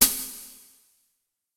Percusión 7: platillo 2
platillo
idiófono
percusión
golpe